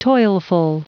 Prononciation du mot toilful en anglais (fichier audio)
Prononciation du mot : toilful